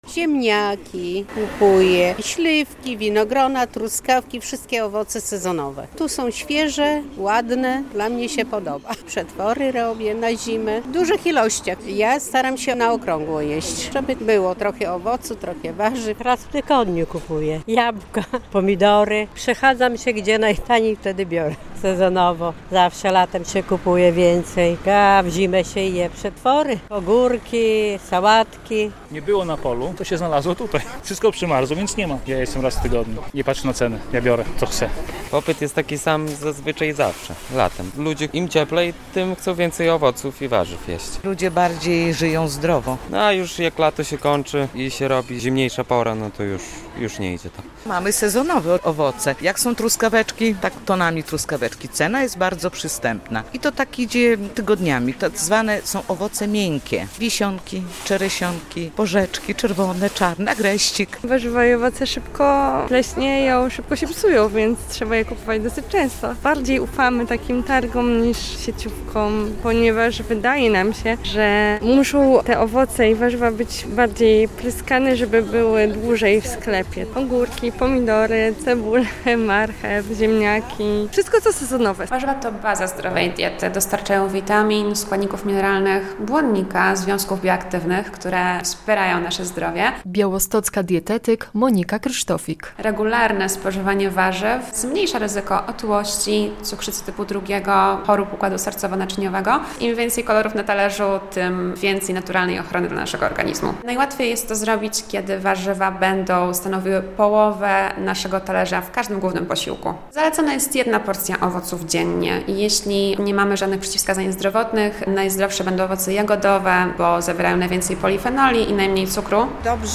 Jemy więcej warzyw - relacja